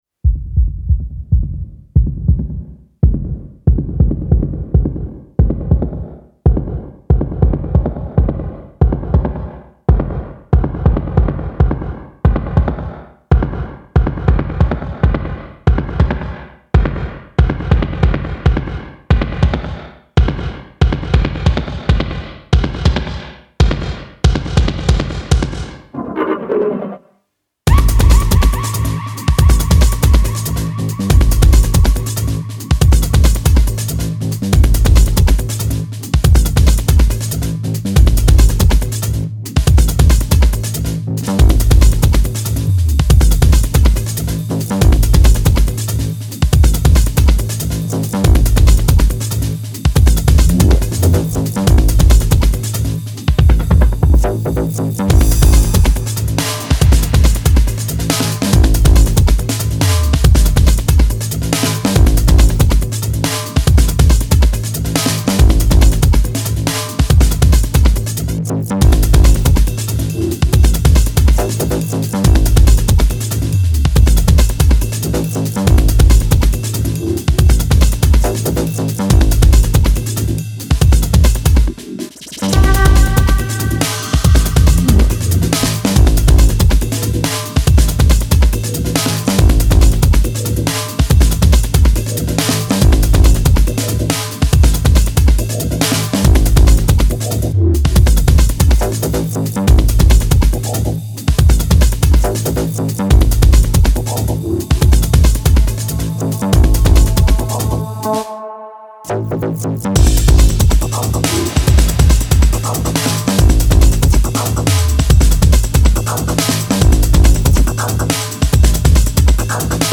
Style: Broken Beat / Breakbeat / Bass Music